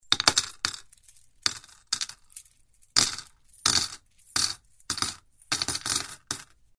На этой странице собраны разнообразные звуки монет: от звонкого падения одиночной монеты до гула пересыпающихся денежных масс.
Звук монет, падающих в копилку